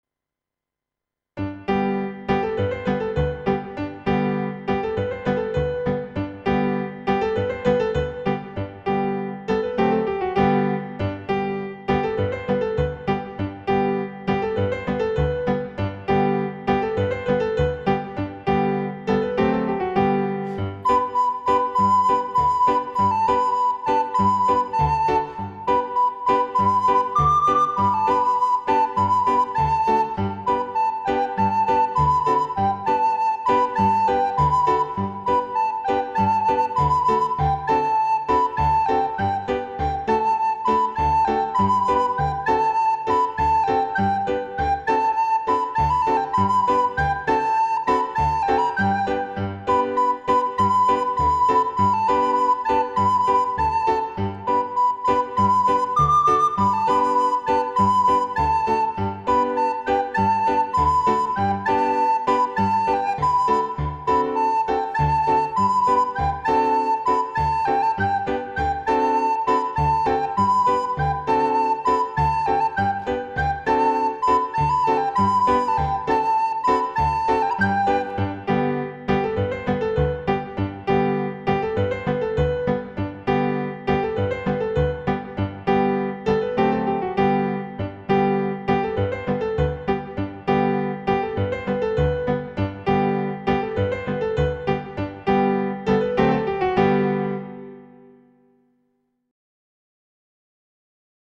• اجرای شاد و منطبق با ساختار نت‌ها
ایرانی